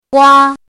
怎么读
guā
gua1.mp3